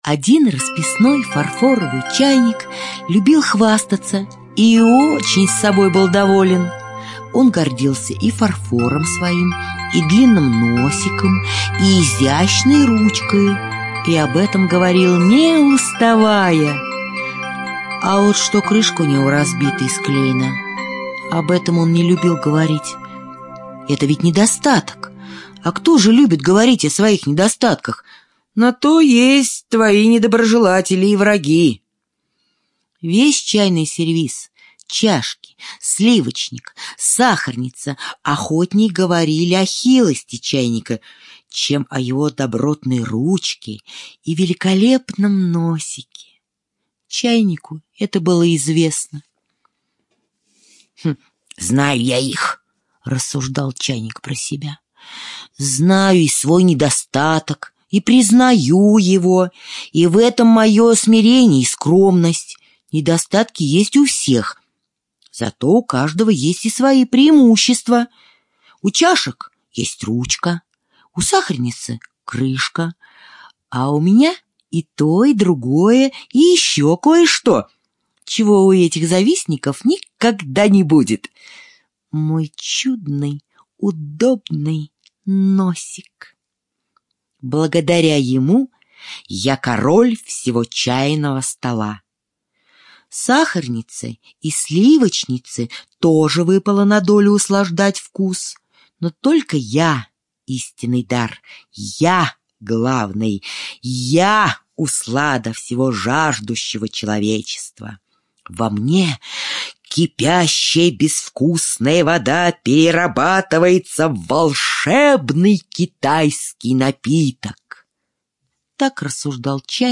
Аудиокнига в разделах